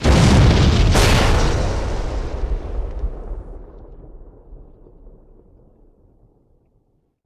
Combat
OtherDestroyed4.wav